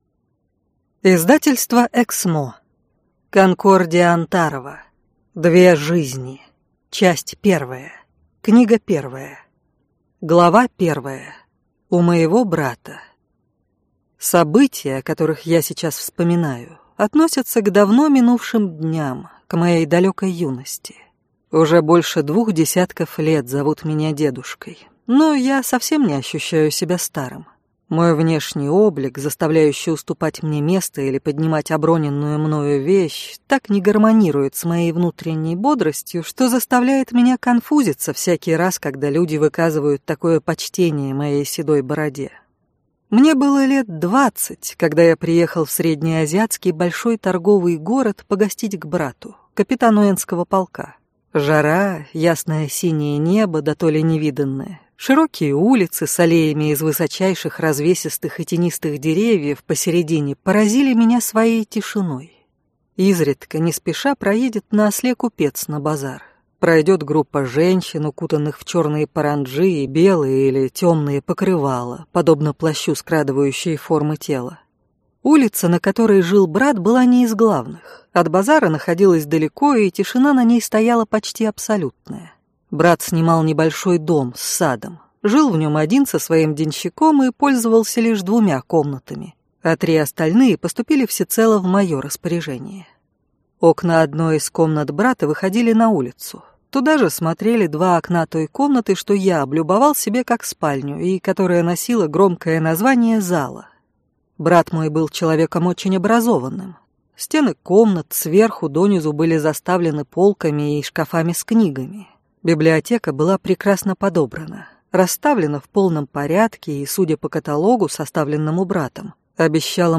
Аудиокнига Две жизни. Часть 1. Книга 1 | Библиотека аудиокниг